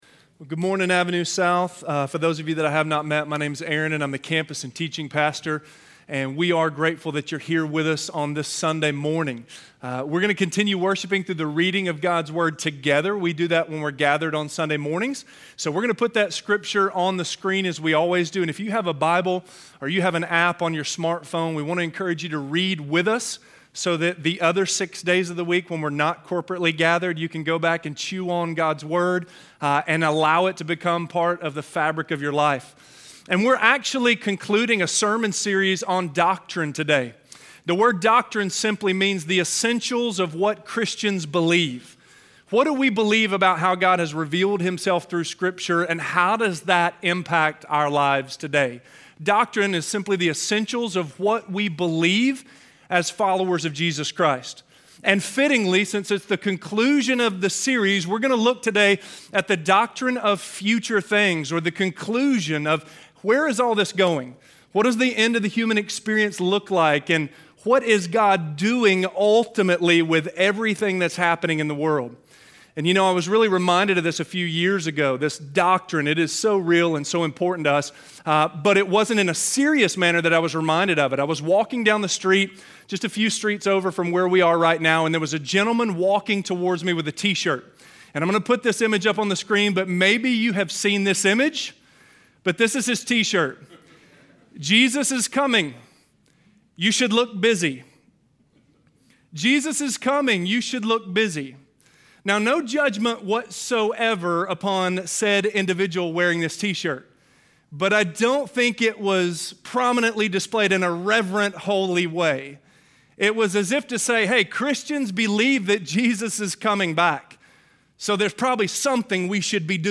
Doctrine of Future Things - Sermon - Avenue South